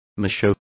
Complete with pronunciation of the translation of macho.